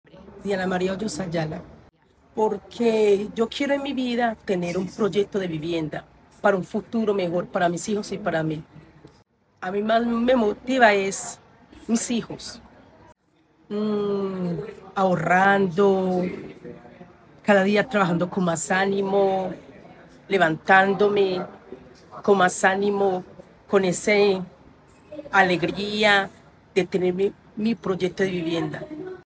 Palabras de beneficiaria